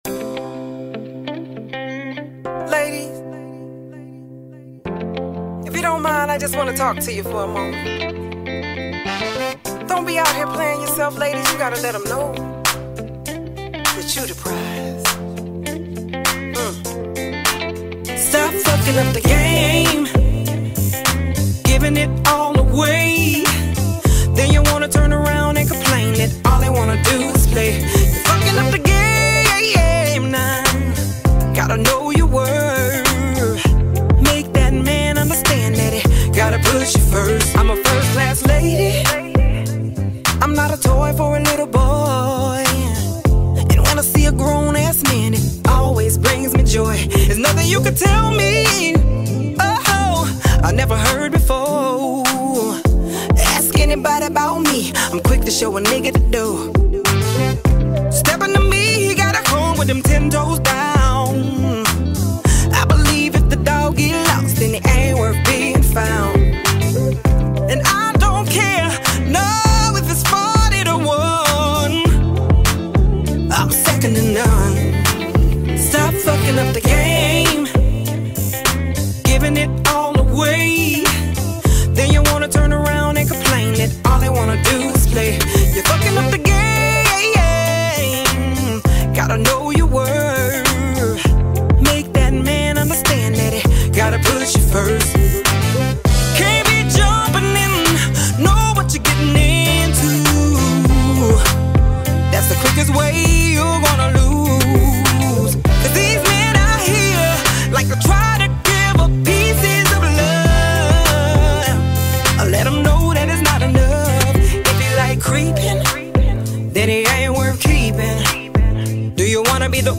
Genre: Southern Soul.